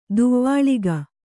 ♪ duvvāḷiga